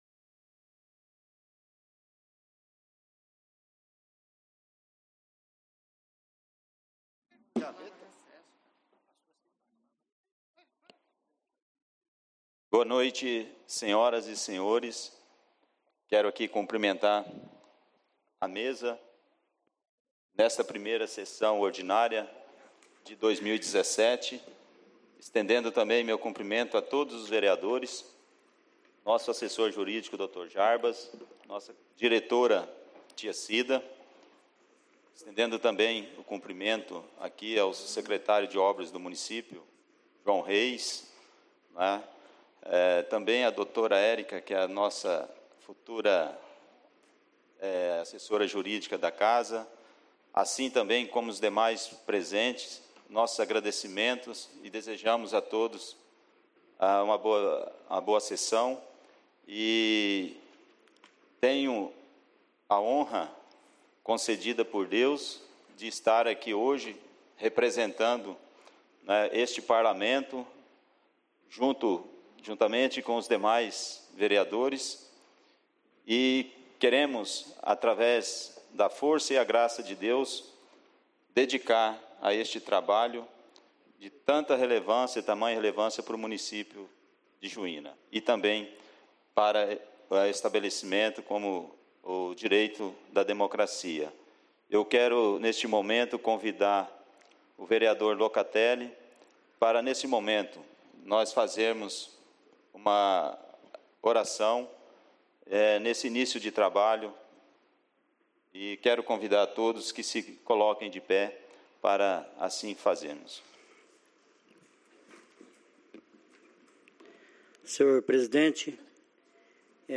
Áudio na íntegra da Sessão Ordinária realizada no dia 06/02/2017 as 20 horas no Plenário Henrique Simionatto.